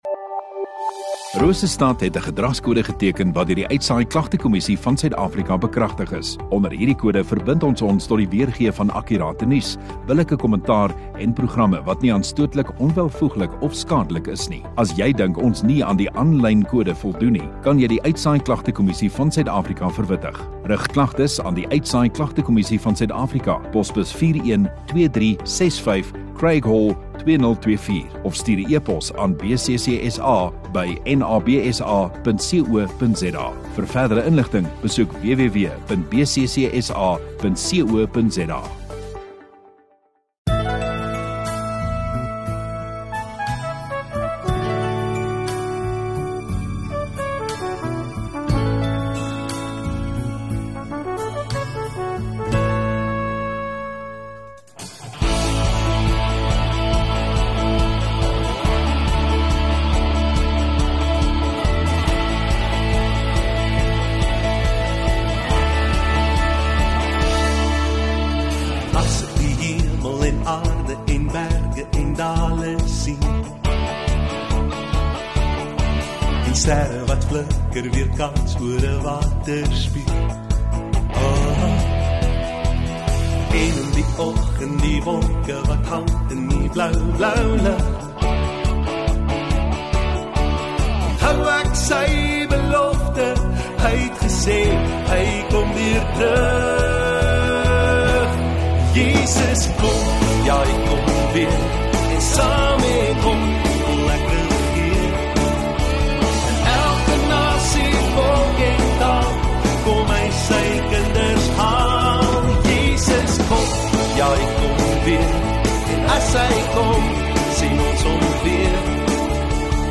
28 Jun Saterdag Oggenddiens